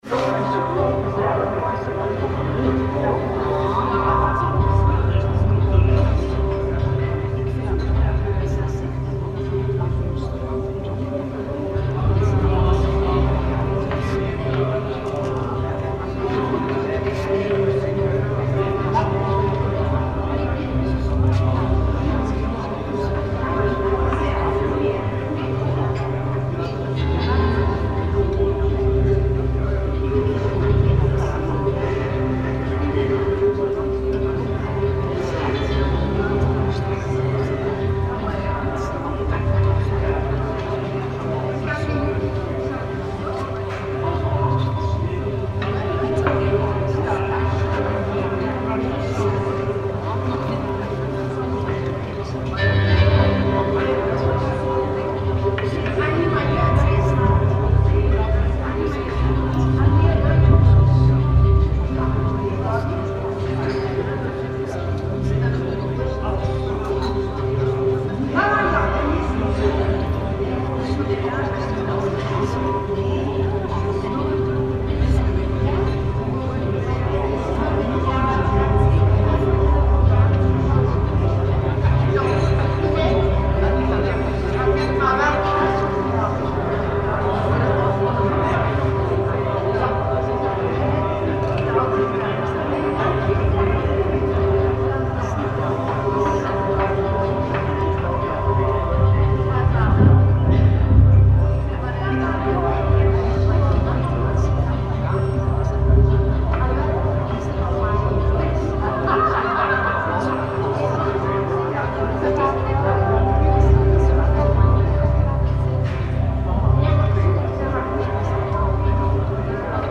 Lake terrace in Lummen, Belgium reimagined